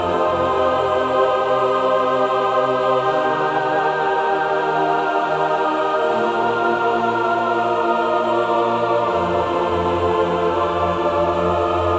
悲伤合唱团第二部分80Bpm
Tag: 80 bpm Pop Loops Choir Loops 2.02 MB wav Key : Unknown